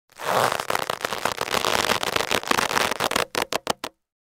Звуки рвущейся веревки или каната, натягивание и связывания для монтажа видео в mp3
8. Звук затягивания узла